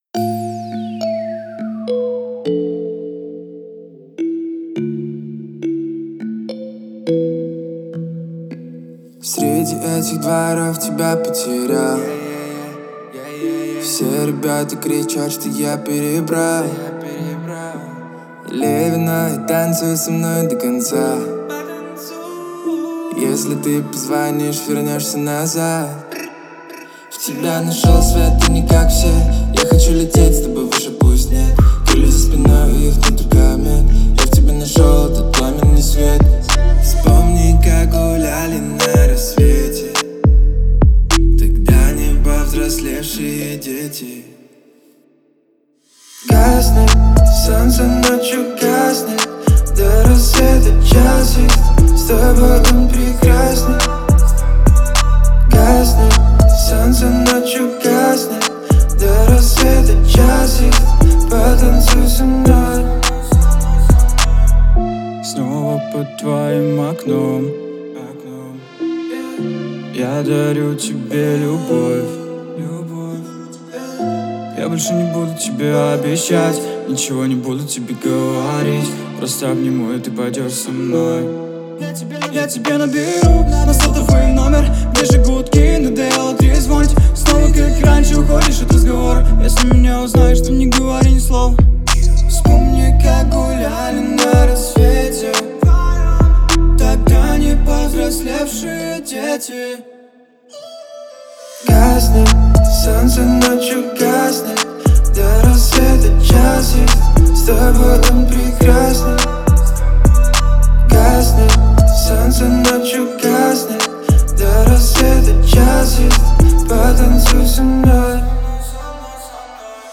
это трек в жанре хип-хоп с элементами R&B